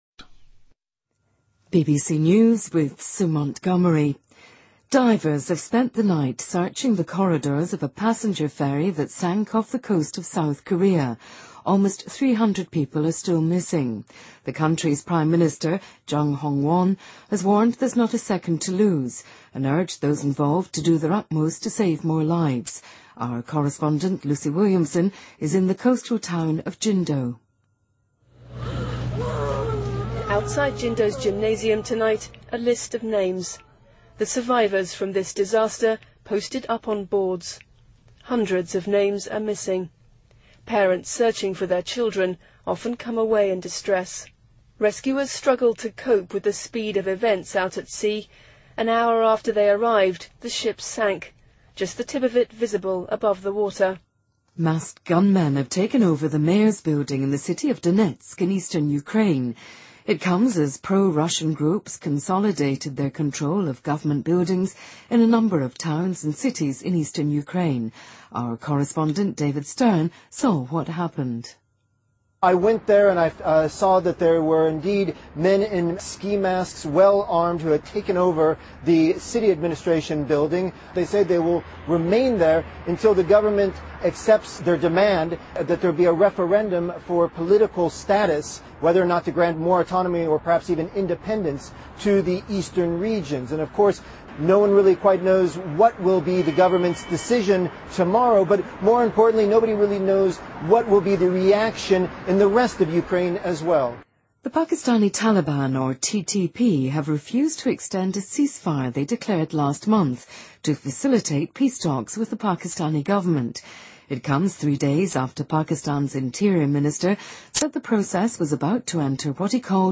BBC news,美国航天局NASA科学家认为土星将产生出其第63个卫星